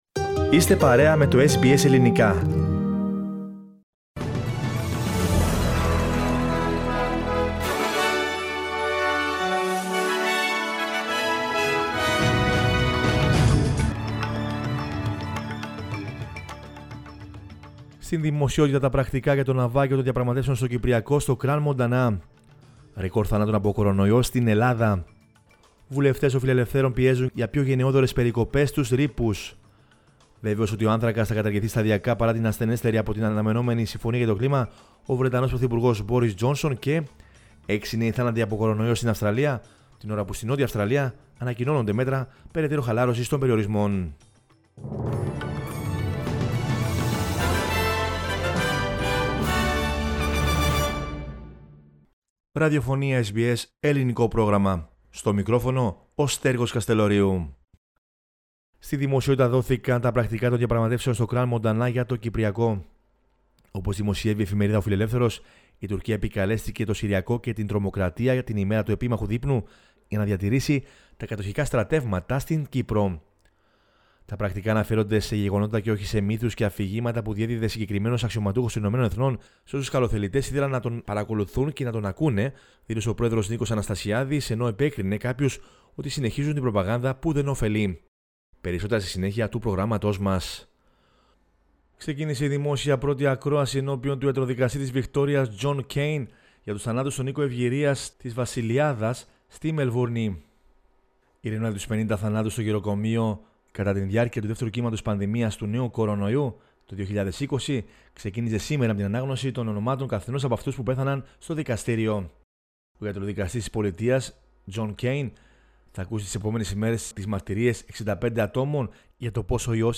News in Greek from Australia, Greece, Cyprus and the world is the news bulletin of Monday 15 November 2021.